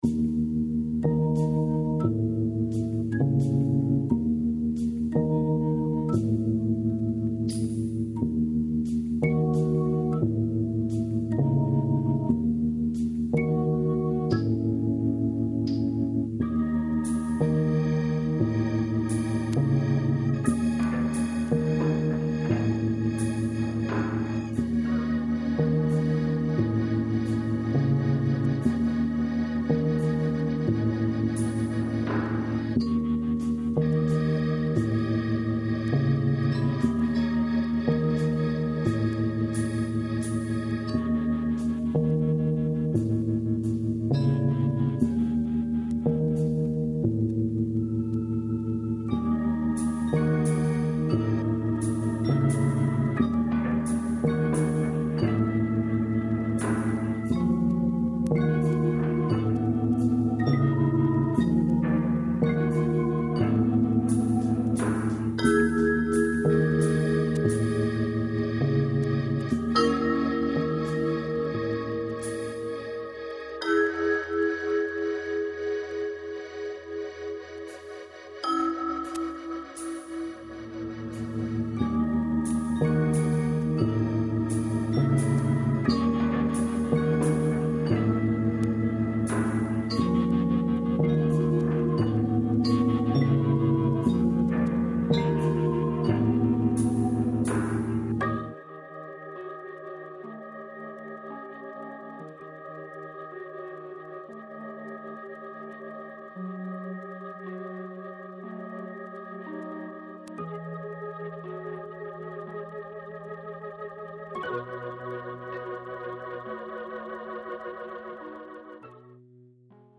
dark, lush and more than a little offbeat.